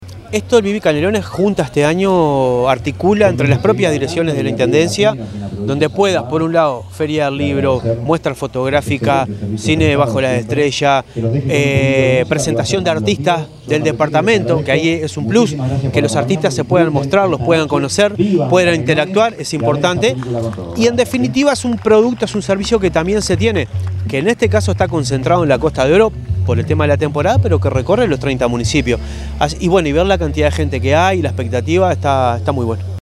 Oratoria del Intendente interino de Canelones, Marcelo Metediera, en la apertura de Viví Canelones en Costa Azul
intendente_interino_de_canelones_marcelo_metediera_1.mp3